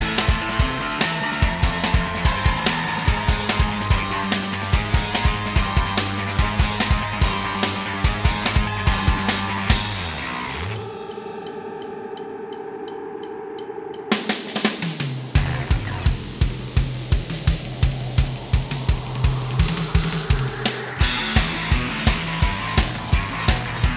Minus Main Guitar Indie / Alternative 3:15 Buy £1.50